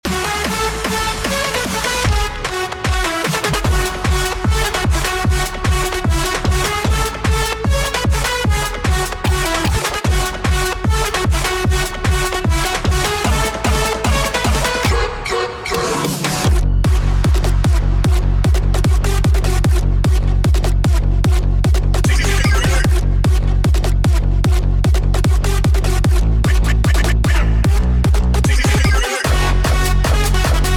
עשיתי עם סונו איזה ביצוע ללחן שלי ניגנתי לו את זה בסך הכול עם פסנתר ואז נתתי לו הוראות מפורטות על סגנון הביצוע כלים וכו’ וזה מה שיצא.
(אני מבטיח לכם שאם לא הייתי אומר לכם שזה AI הייתם בטוחים שזה איזה פסקול מסרט)